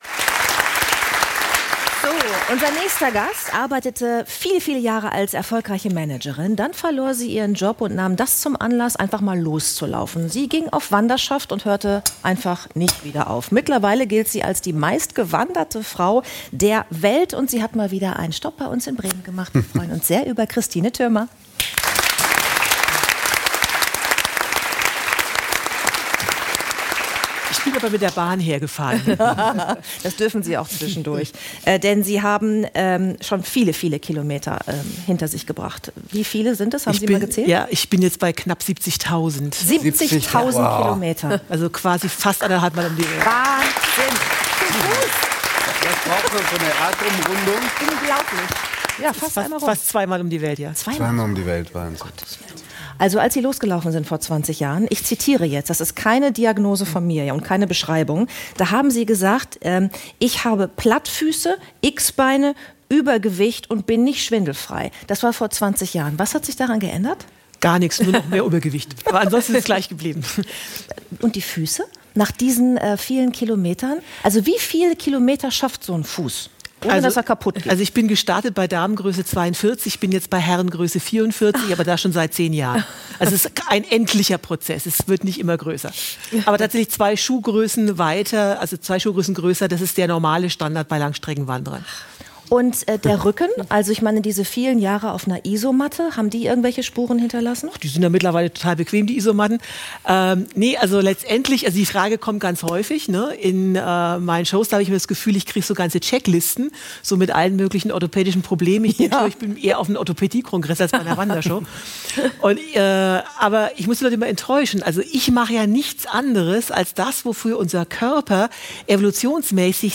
Christine Thürmer – Langstreckenwanderin ~ 3nach9 – Der Talk mit Judith Rakers und Giovanni di Lorenzo Podcast
Bei 3nach9 erzählt die Bestsellerautorin über ihre Abenteuerreise nach Asien und berichtet über verrückte Erlebnisse in Japan, Südkorea und Taiwan.